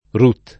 Rut [ rut ] → Ruth